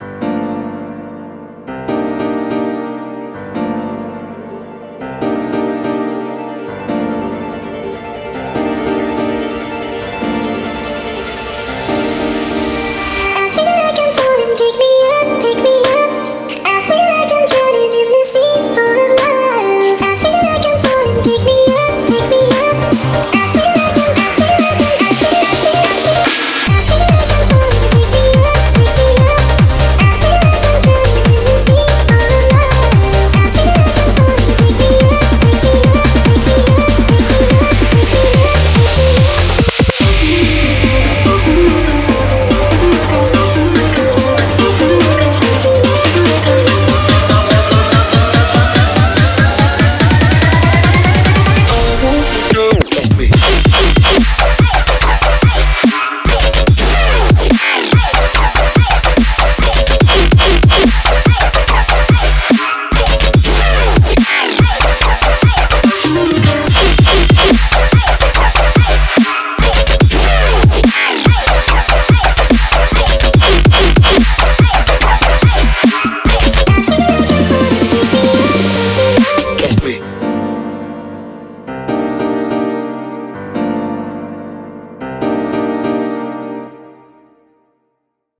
BPM72-144
MP3 QualityMusic Cut